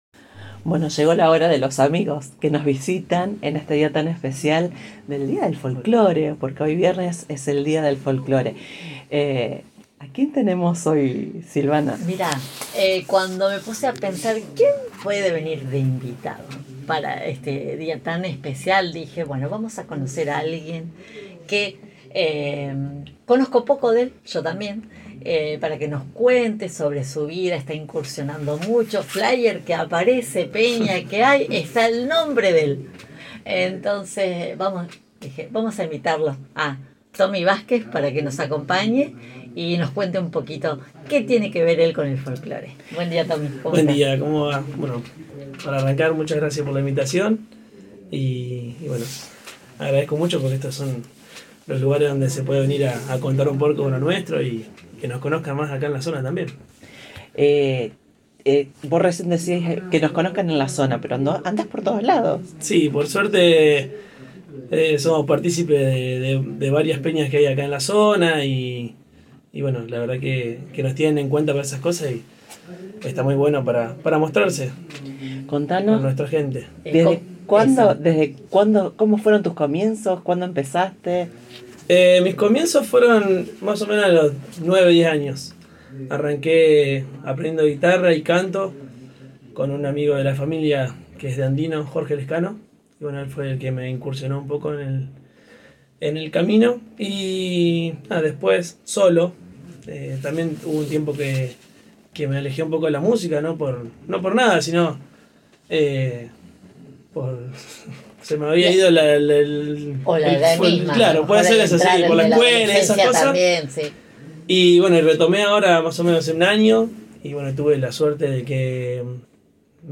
¡Una charla con alma y guitarra!